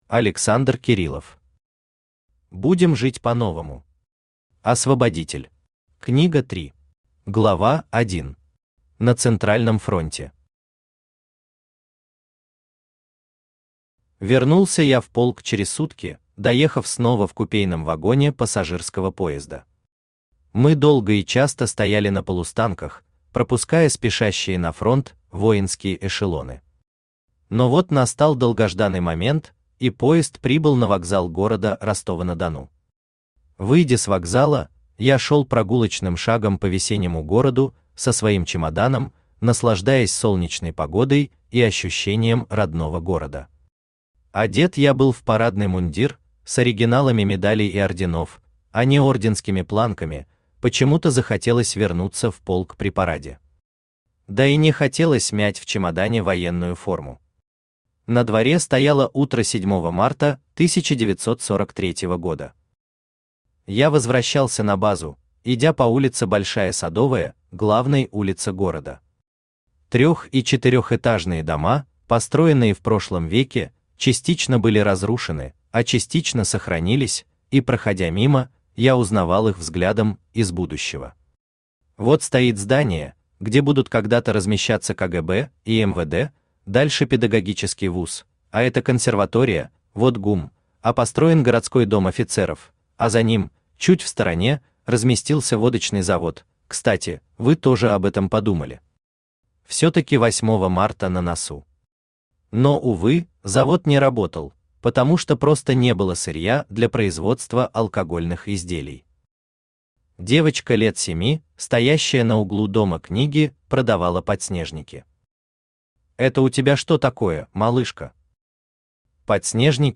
Аудиокнига Будем жить по-новому! Освободитель. Книга 3 | Библиотека аудиокниг
Книга 3 Автор Александр Леонидович Кириллов Читает аудиокнигу Авточтец ЛитРес.